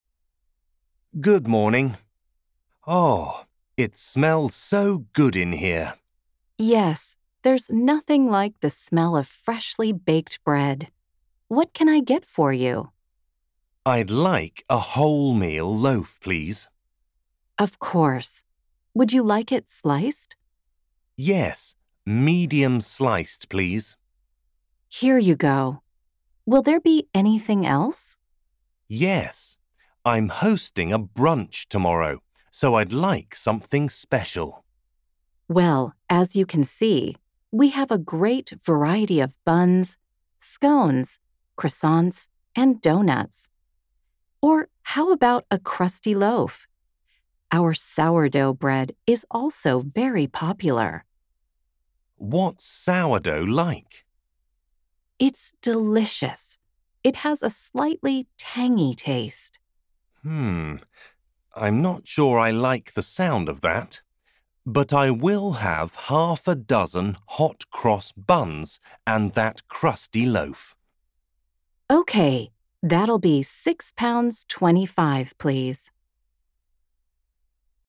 Speaker (UK accent)
Speaker (American accent)
Dialogo in inglese: nella panetteria